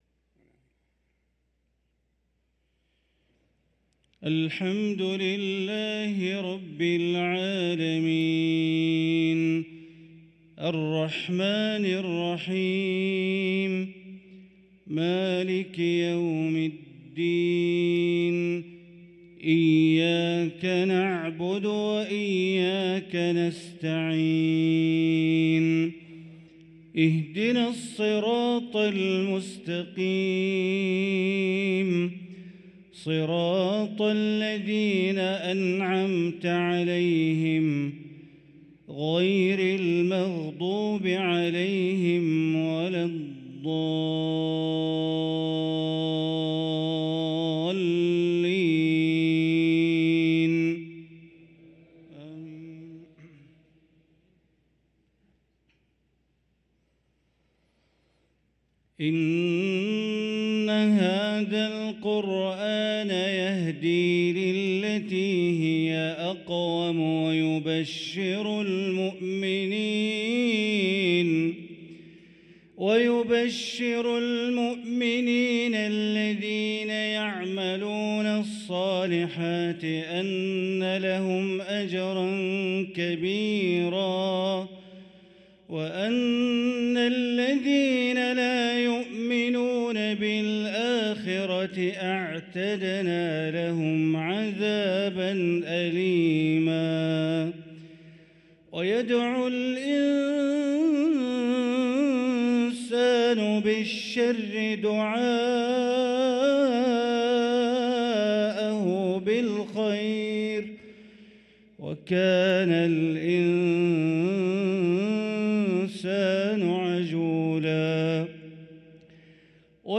صلاة العشاء للقارئ بندر بليلة 26 صفر 1445 هـ
تِلَاوَات الْحَرَمَيْن .